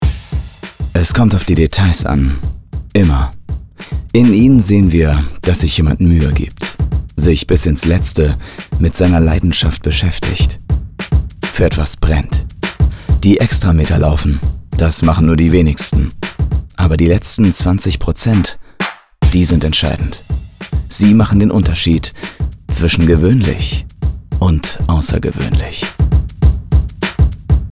sehr variabel, markant, hell, fein, zart, plakativ
Off, Audiobook (Hörbuch)